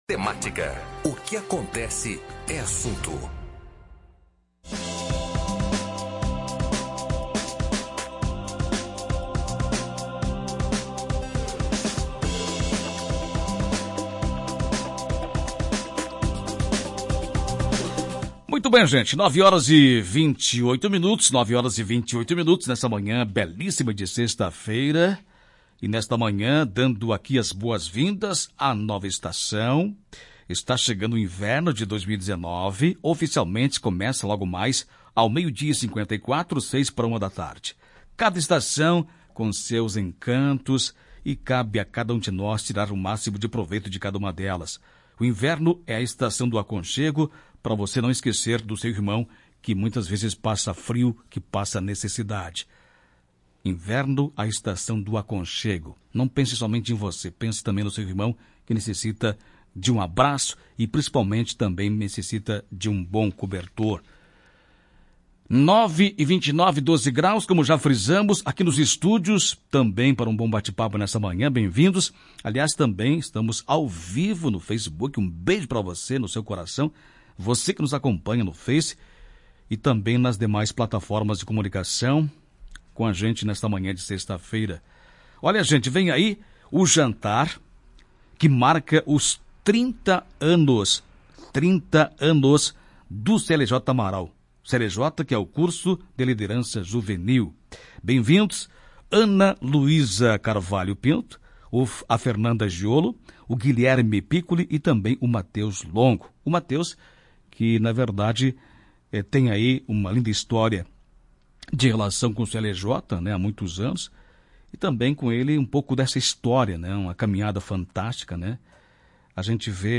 Ouça a entrevista completa no áudio da matéria.